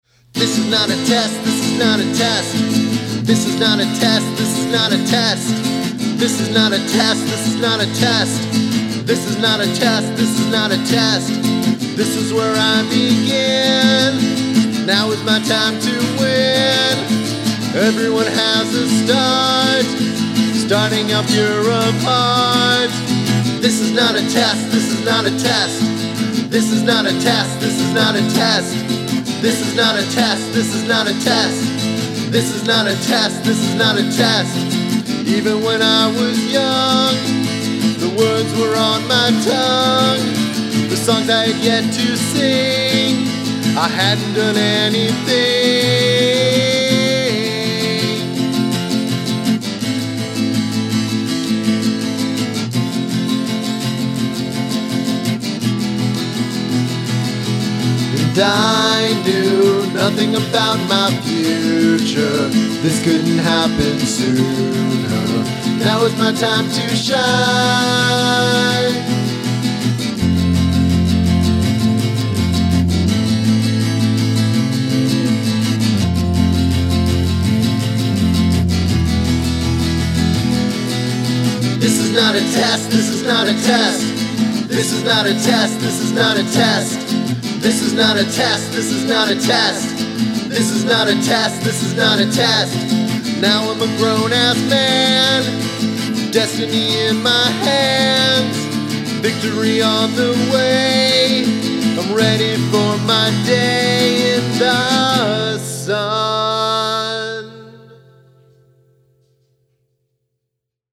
😉 Nice guitar, but not very interesting.